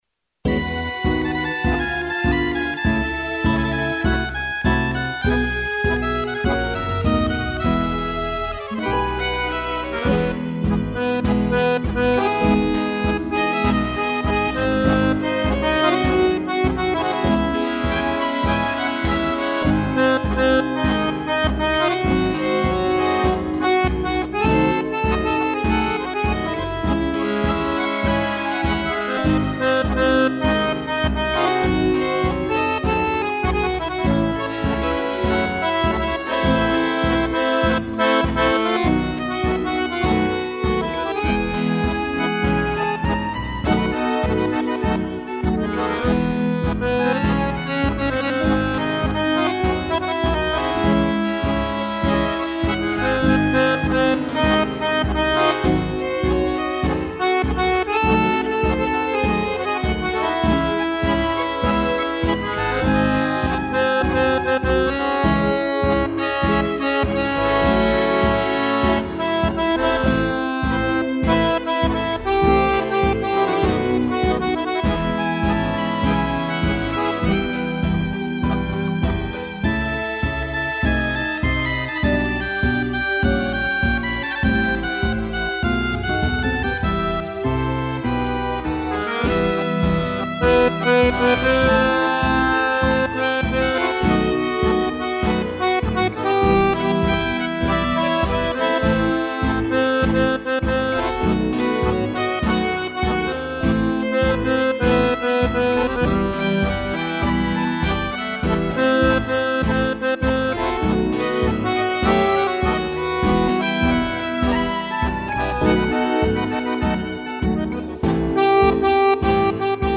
tango
acordeon base e violino